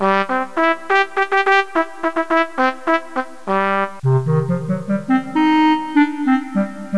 Cada fitxer s'haura de desar en 11025 Hz, mono i 8 bits.
5. Recupereu ara el fitxer trumpet.wav. Seleccioneu des del segon 4 al final i suprimiu aquest tros d'ona.
6. Afegiu el fitxer clarinet.wav i un cop inserit elimineu a partir del segon 7. Deseu el firxer a la carpeta de treball amb el nom vent.wav.
vent.wav